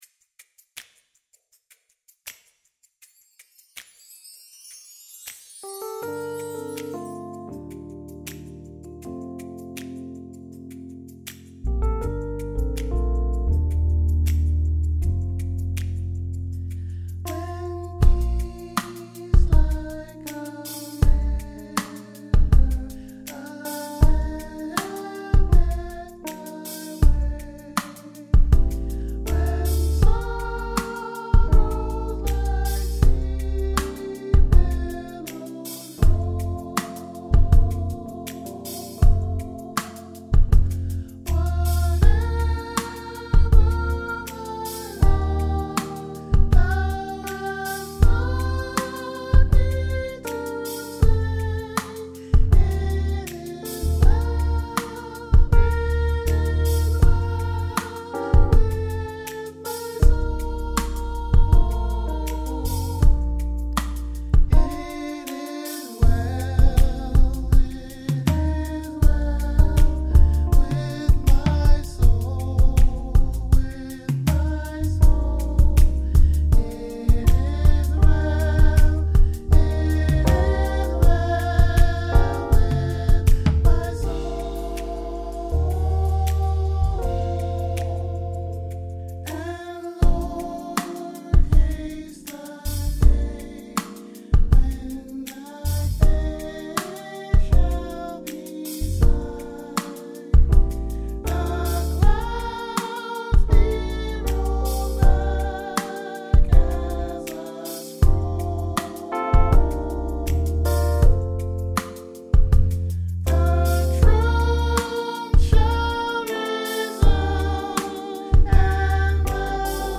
IT IS WELL: SOPRANO